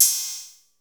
Index of /musicradar/essential-drumkit-samples/Vintage Drumbox Kit
Vintage Cymbal 02.wav